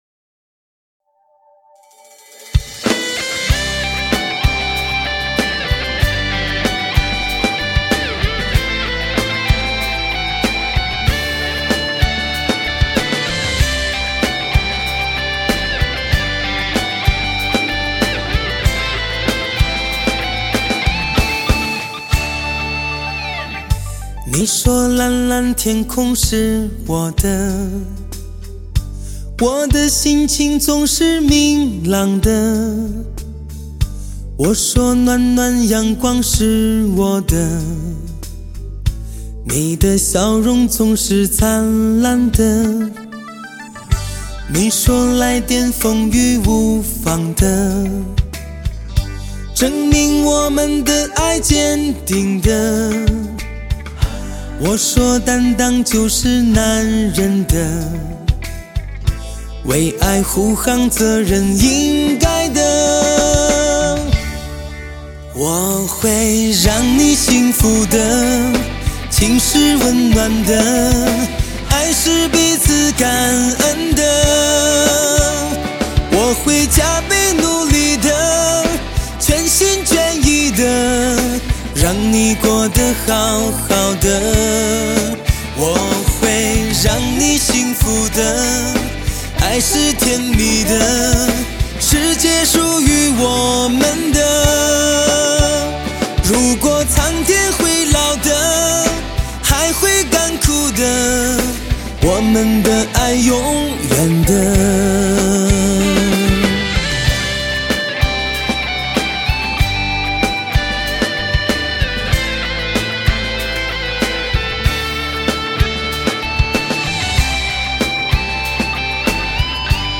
车载汽车发烧音乐光盘
让人瞬间喜欢上这扣人心扉的声音